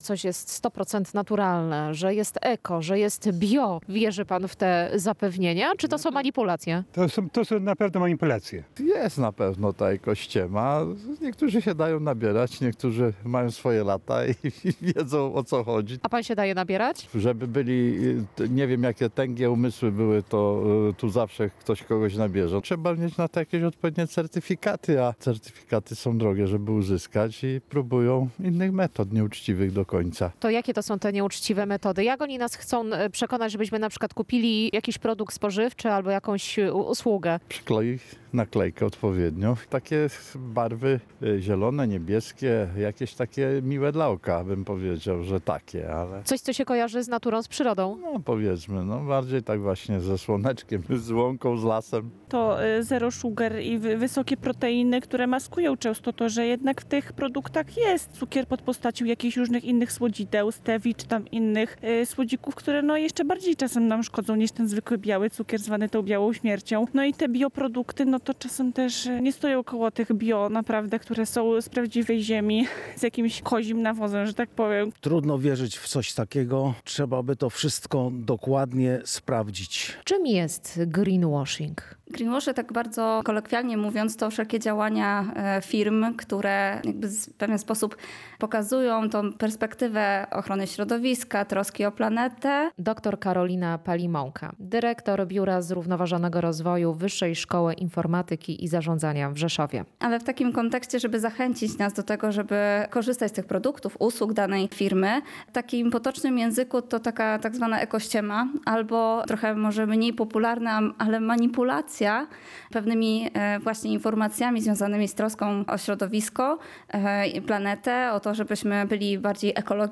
Relacje reporterskie • Eko, bio, naturalne - takie hasła coraz częściej pojawiają się na produktach i w reklamach.